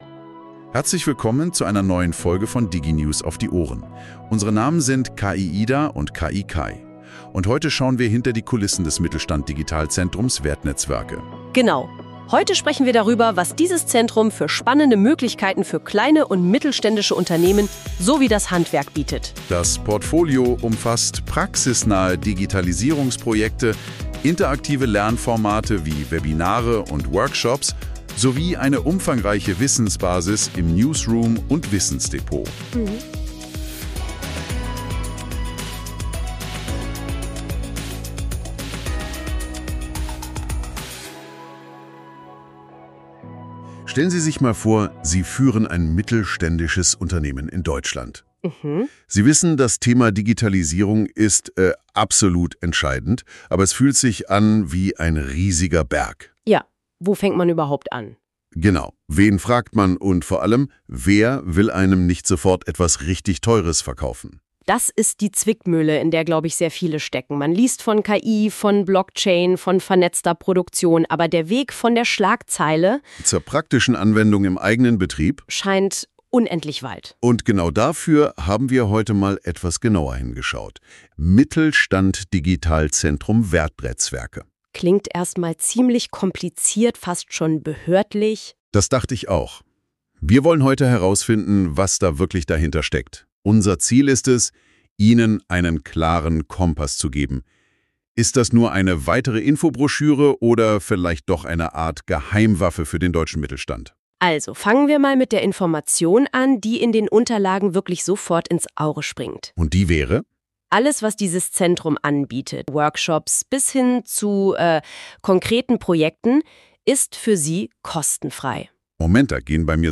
In unserer neuen, vollständig KI-generierten Podcast-Folge führt Sie ein besonderes Duo durchs Mittelstand-Digital Zentrum WertNetzWerke: unsere Stimmen KI-Ida und KI-Kai. Die Folge zeigt, wie Zukunftstechnologien nicht nur Thema, sondern auch aktiver Teil unserer Arbeit und Kommunikation bereits sind und werden.